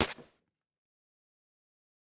pistol_2.ogg